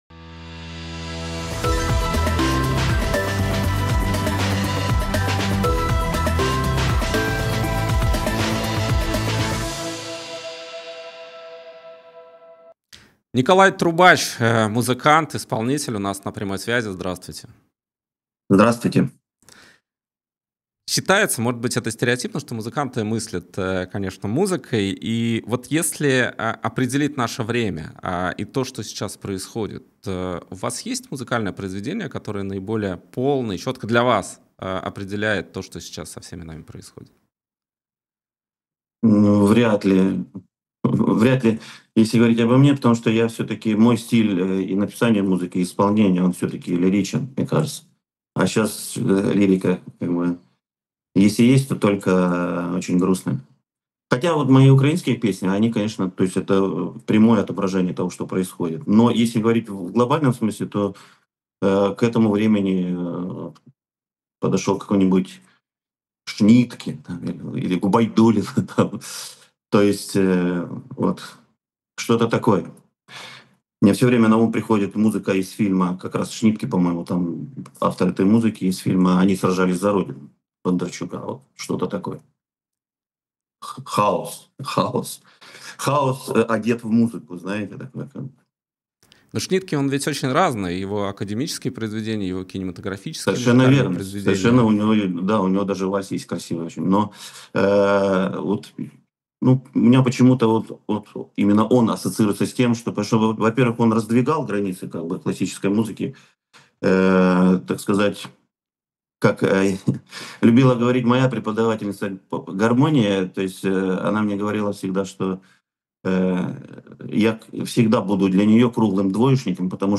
«И грянул Грэм» с Николаем Трубачом: Что случилось с «Голубой Луной», Моисеев, Пугачева, звонок Эрнста, где Ротару, Украина